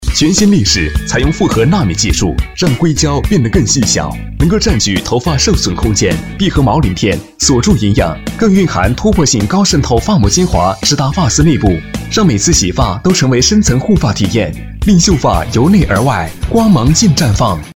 广告男247号（力士品牌）
年轻时尚 品牌广告